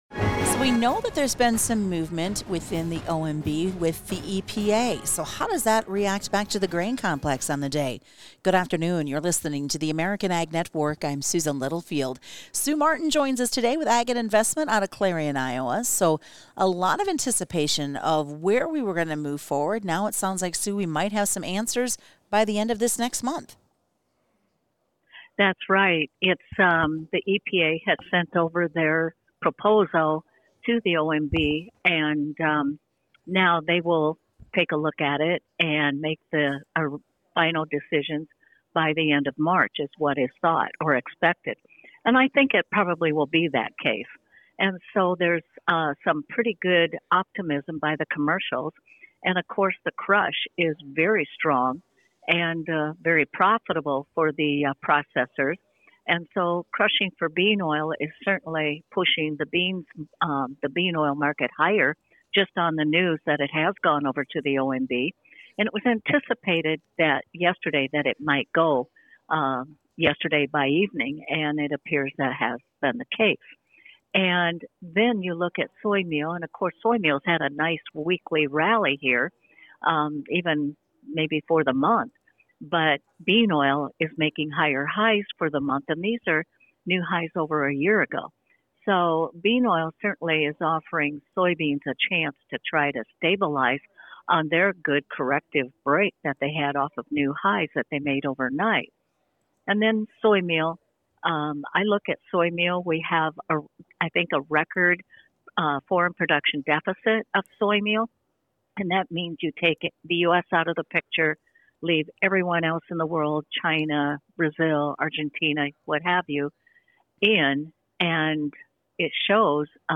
Thursday Market Wrap Up